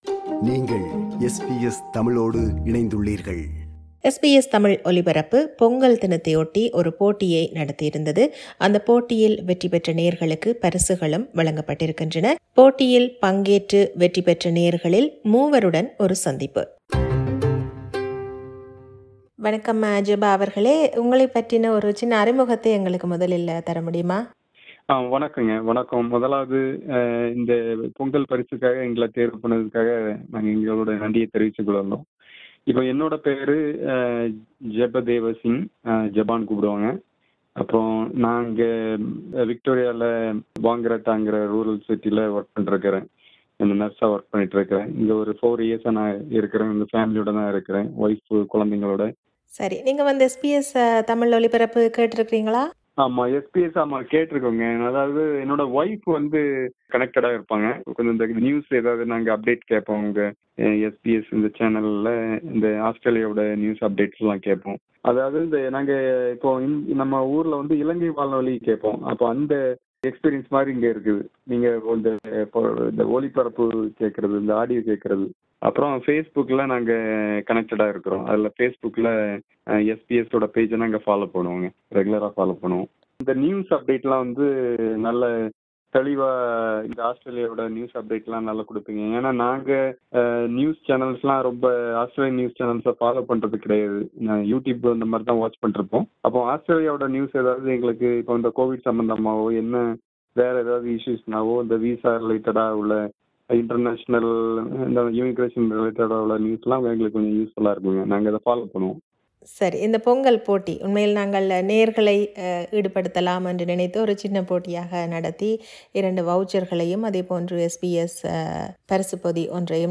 Interview with three lucky winners of SBS Tamil’s pongal competition
SBS Tamil ran 'ThaiPongal 2022' competition for those celebrating Pongal in Australia, with two $100 Coles gift cards or one of five SBS prize packs up for grabs. This is an interview with three winners of this year's Thai Pongal competition 2022.